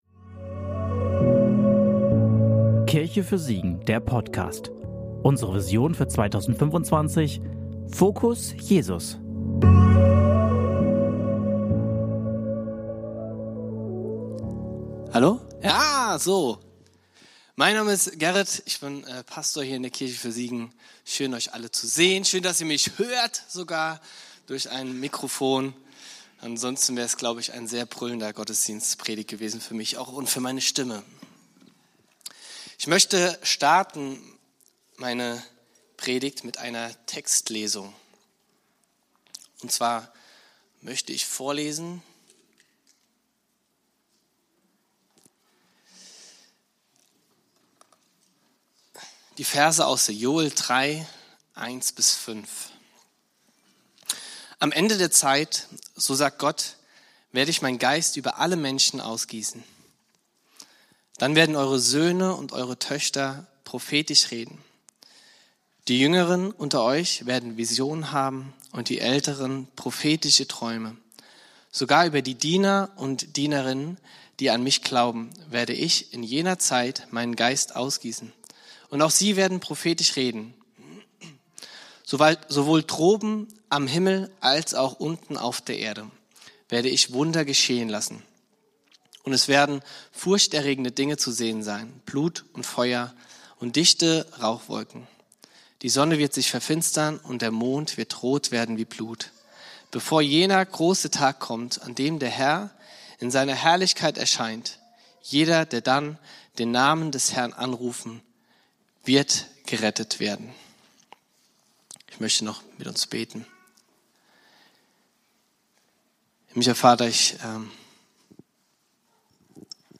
Predigt vom 08.03.2026 in der Kirche für Siegen